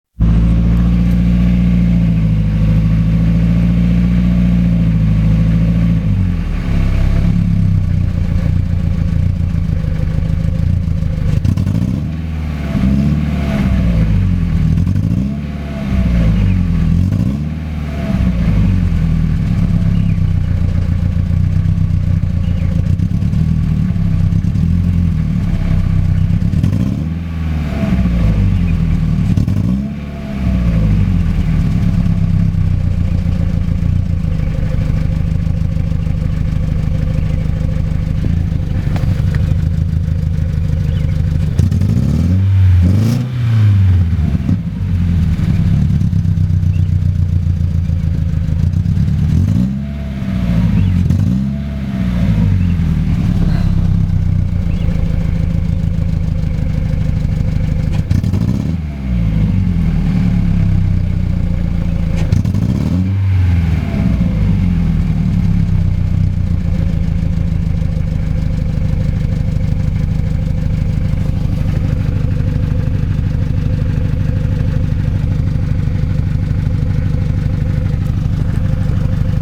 Quand je démarre ma voiture, mon moteur ne tourne pas rond, c'est comme si il tournait sur 3 cylindre, comme si un injecteur ne fonctionnait pas, quelque chose n'est pas synchro, quand j'appuie sur la pédale d'accélérateur on s'appercoit que ce n'est pas le bruit d'un moteur qui tourne bien, à écouter la bande son ici